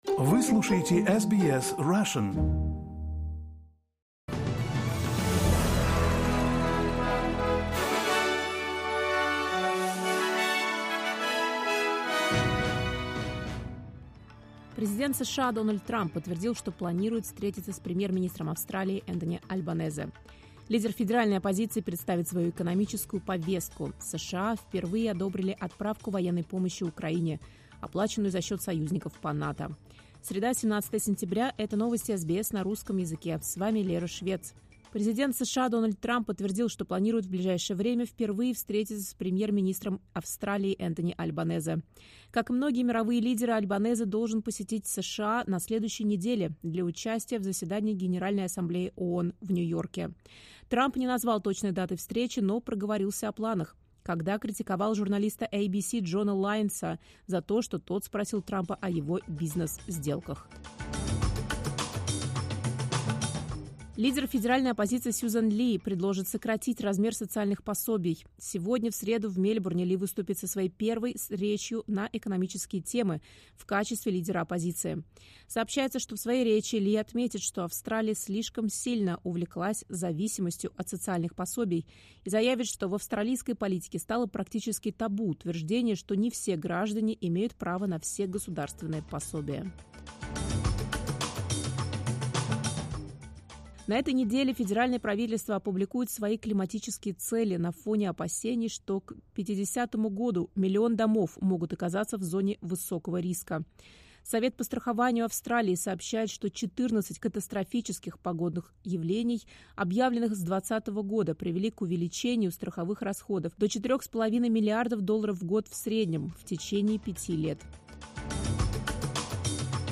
Новости SBS на русском языке — 17.09.2025